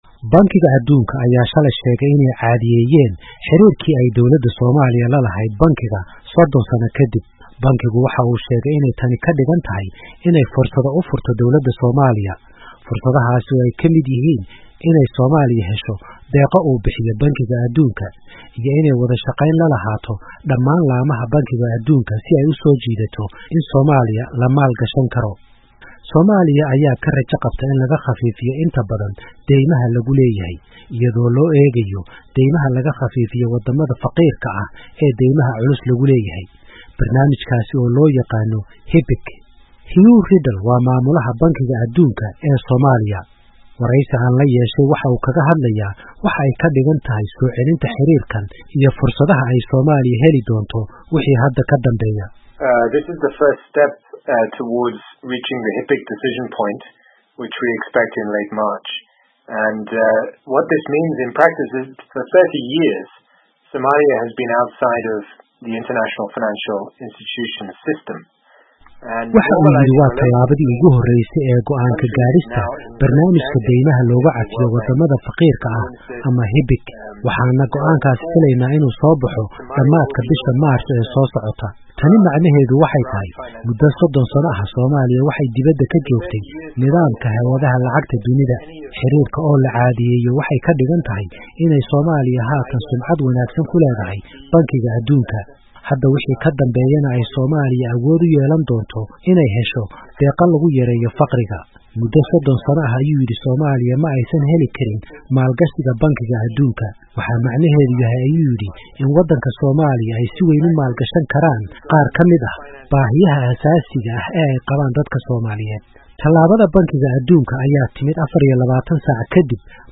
Waraysiga Bankiga Adduunka